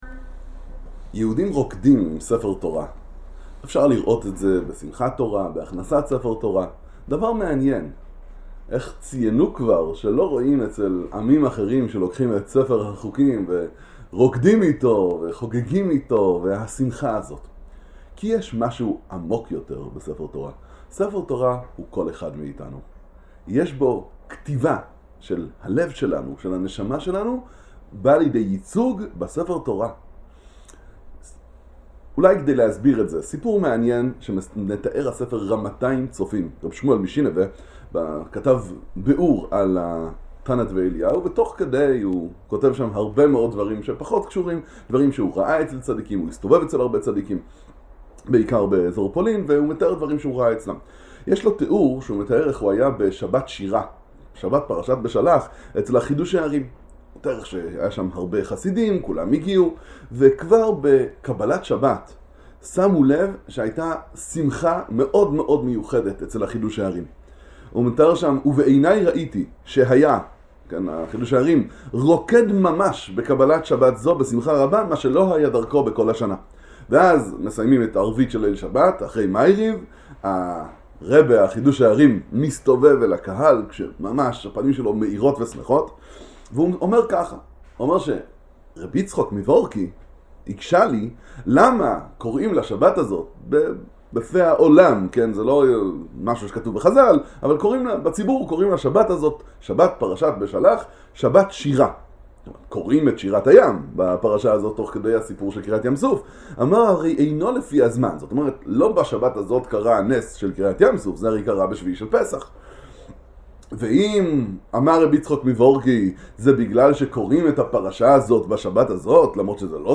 דרשה הכנסת ספר תורה